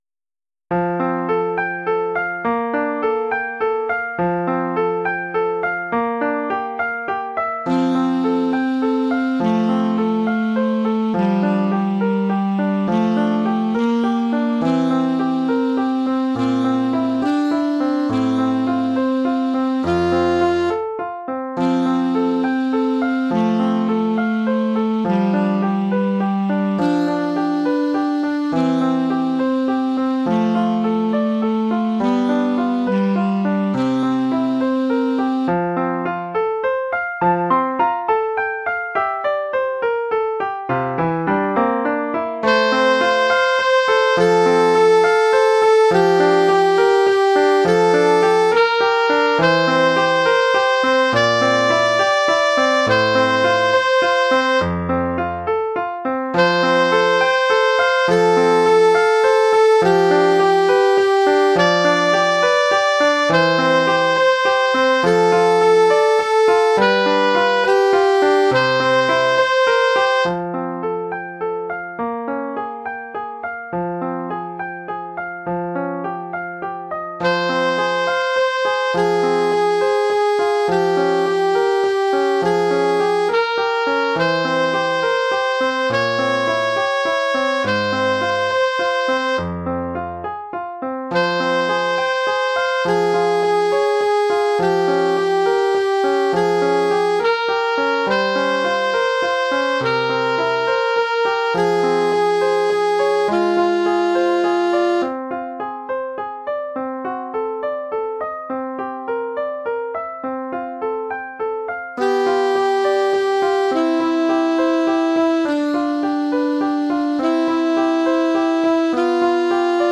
Oeuvre pour saxophone alto et piano.